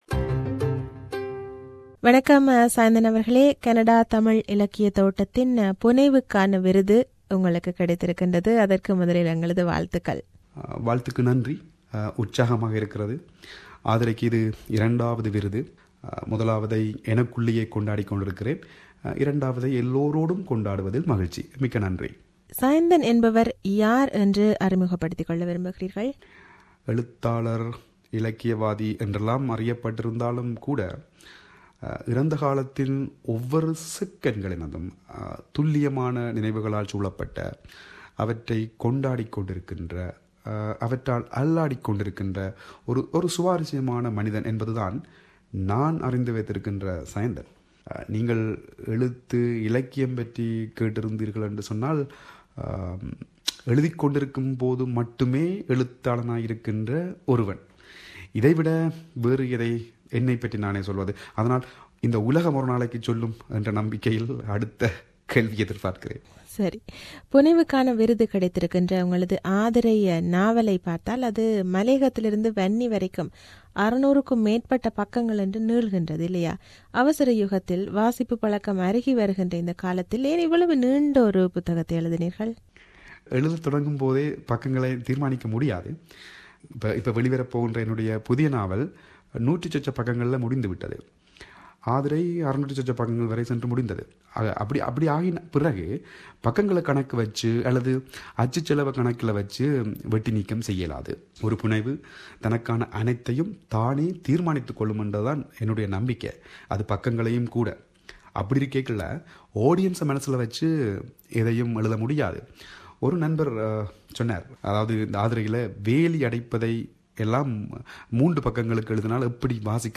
இது தொடர்பில் அவரோடு ஒரு சந்திப்பு.